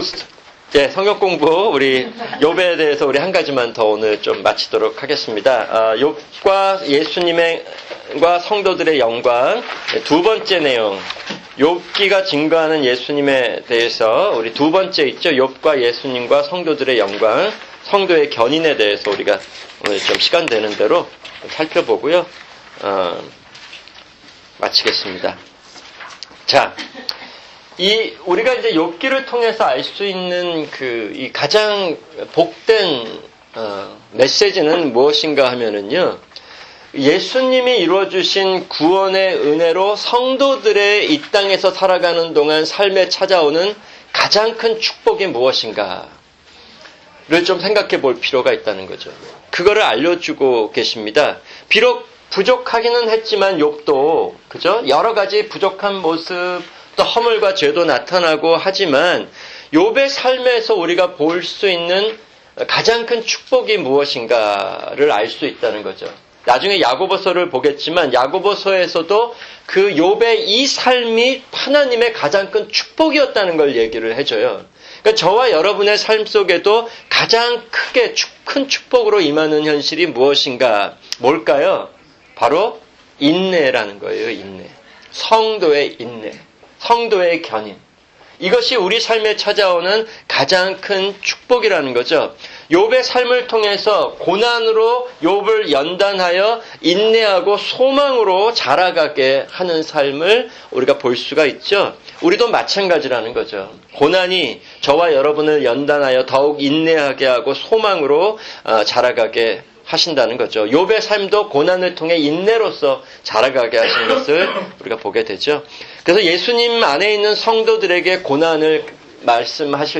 [주일 성경공부] 성경개관-욥기(10)